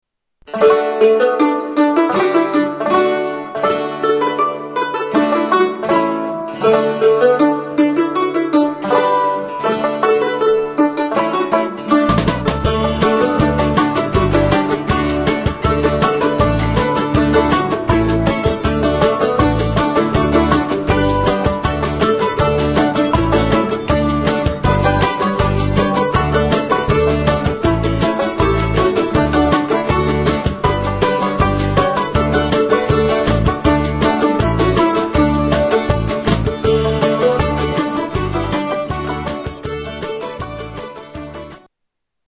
Square Dance
Barn Dance